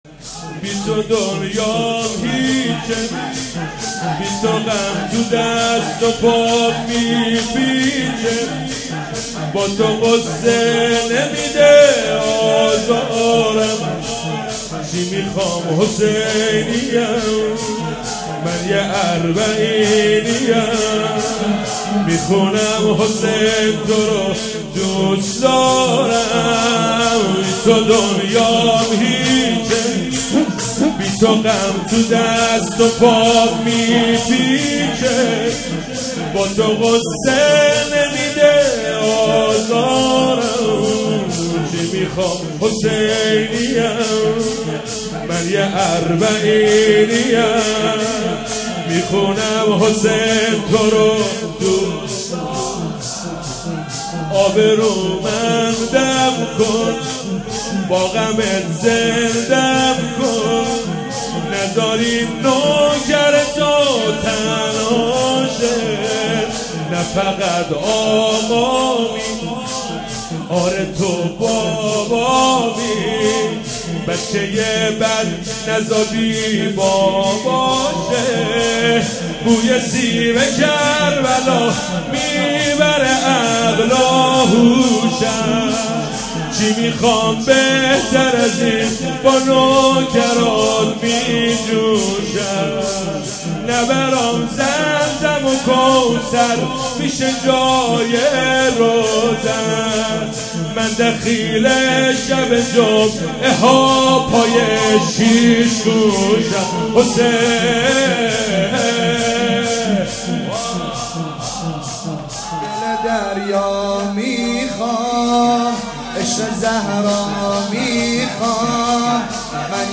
شب بیستم رمضان ۹۷ هيـأت فاطمـيون ماهشهـر